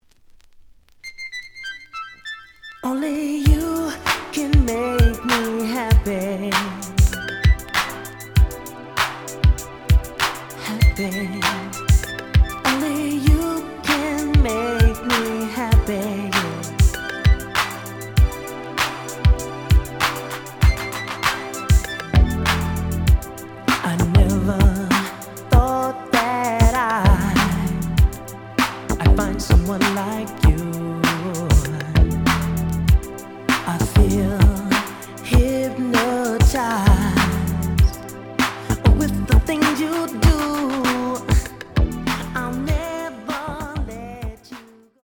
The audio sample is recorded from the actual item.
●Genre: Soul, 80's / 90's Soul